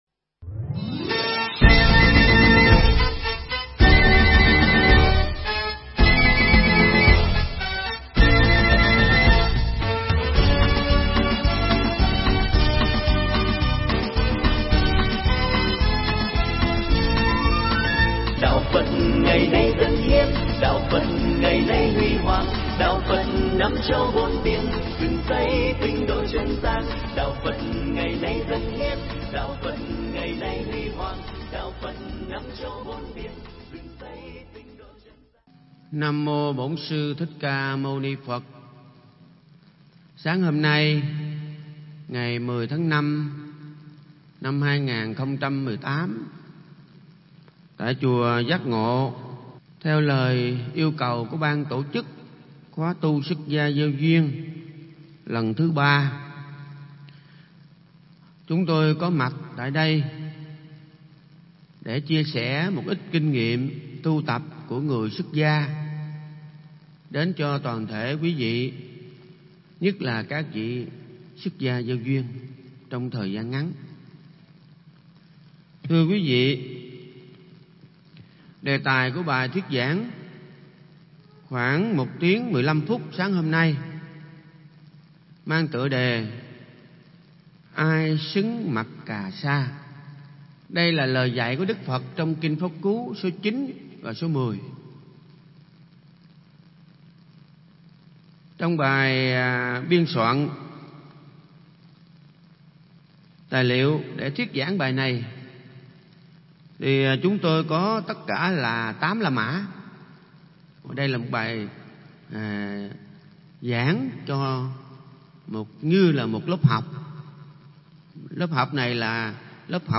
Mp3 Pháp Thoại Ai Xứng Mặc Cà Sa
giảng trong khóa tu Xuất Gia Gieo Duyên kỳ 3 tại chùa Giác Ngộ